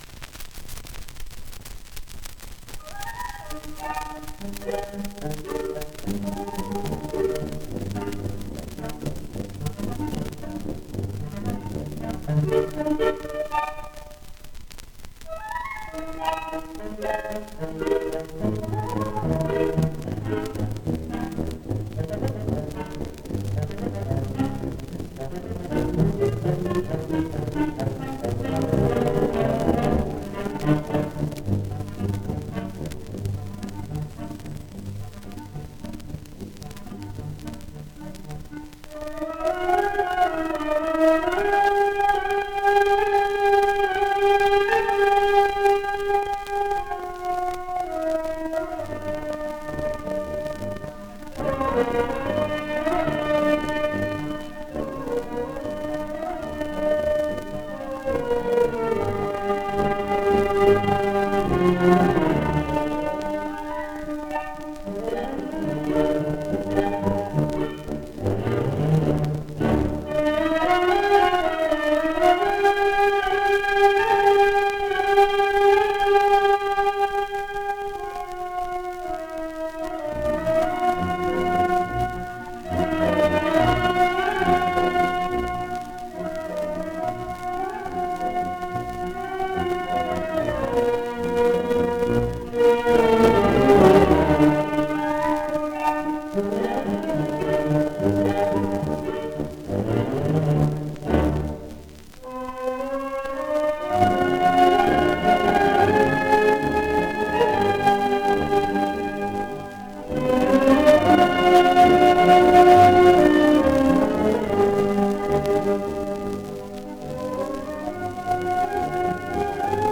1 disco : 78 rpm ; 30 cm Intérprete